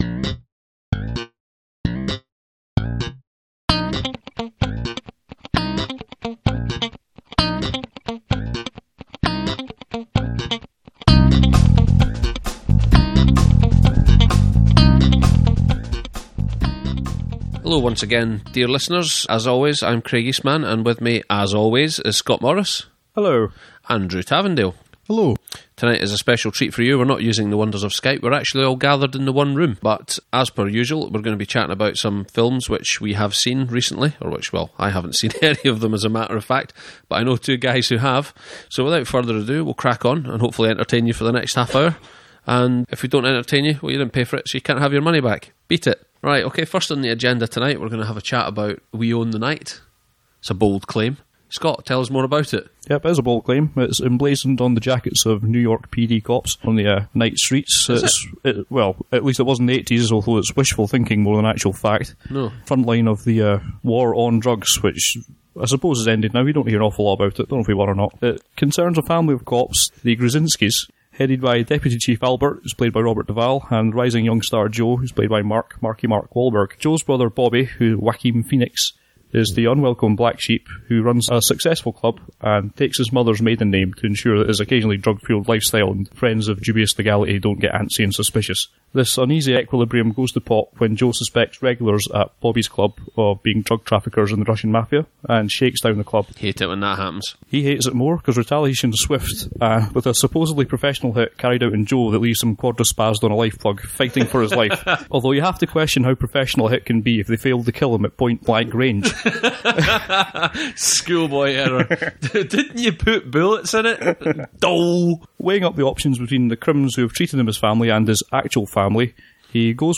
This time round we talk about, in somewhat more robust language than usual, We Own The Night, family drama slash police procedural that never struggles above mediocre despite the good intentions of all involved.
And I'm not joking about the explicit tag on this podcast this time, there's some rather fruity language so consider yourself further warned.